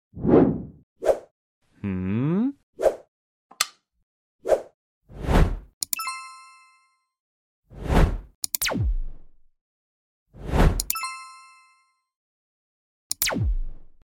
Touch ON OFF switch circuit sound effects free download